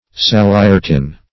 Search Result for " saliretin" : The Collaborative International Dictionary of English v.0.48: Saliretin \Sal`i*re"tin\, n. [Saligenin + Gr.